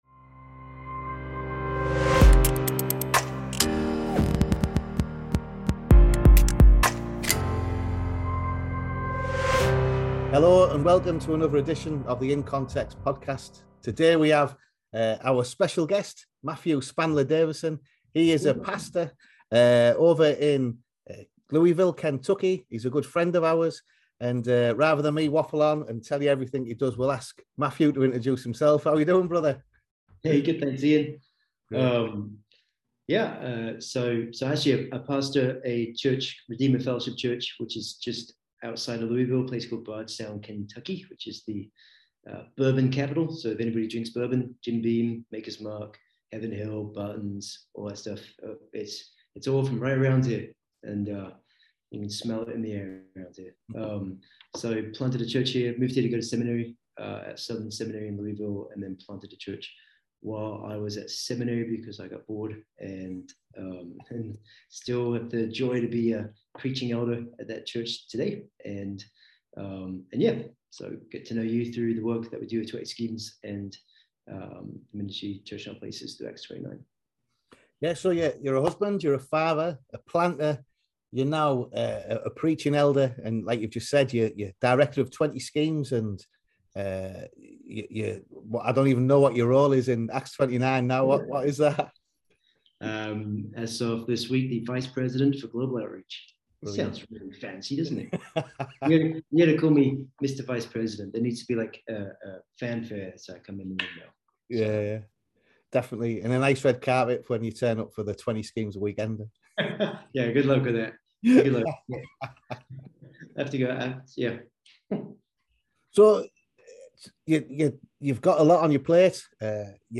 Interview the Board